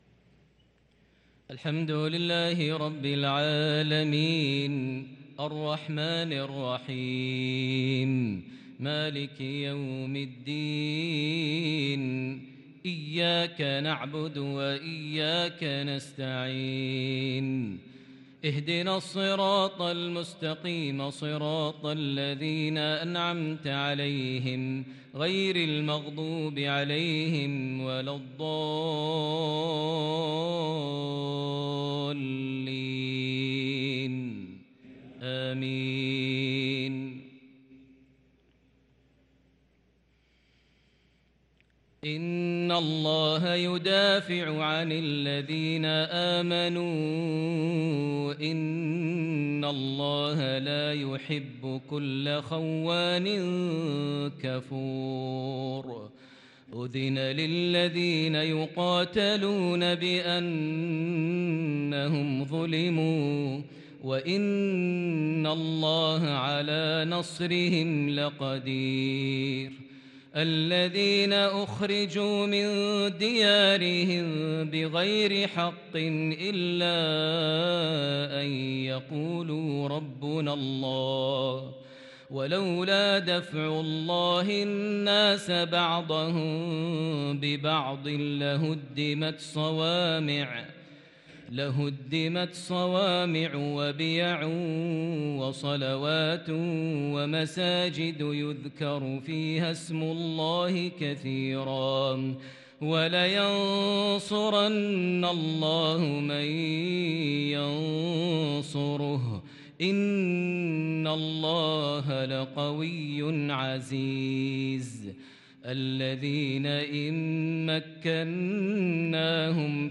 صلاة العشاء للقارئ ماهر المعيقلي 3 ذو الحجة 1443 هـ
تِلَاوَات الْحَرَمَيْن .